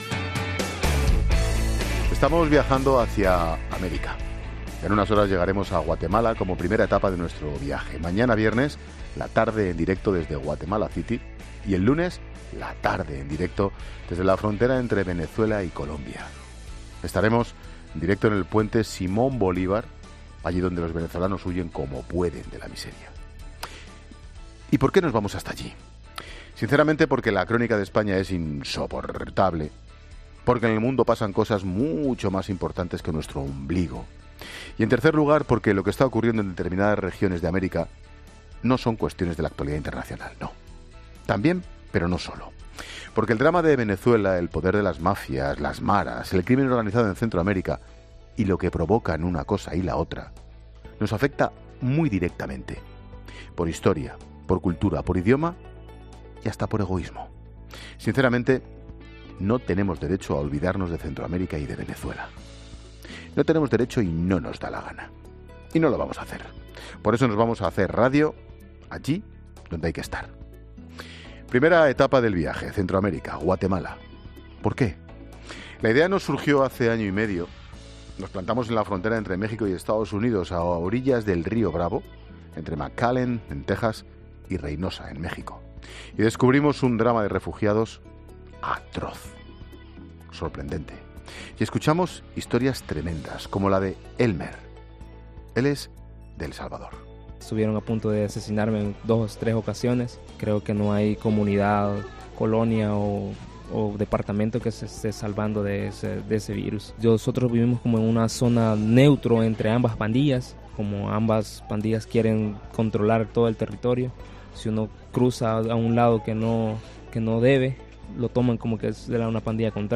Monólogo de Expósito
El comentario de Ángel Expósito de camino a Guatemala.